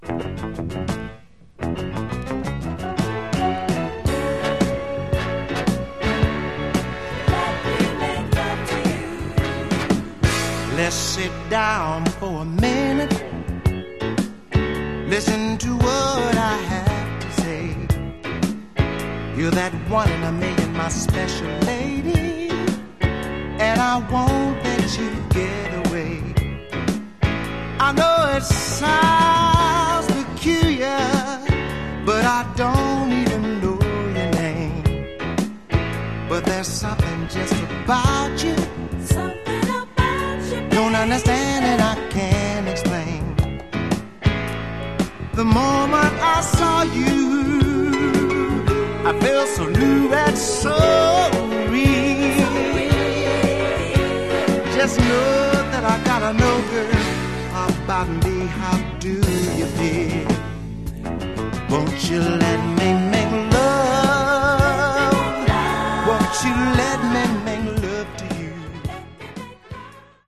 Category: Philly Style   $7.00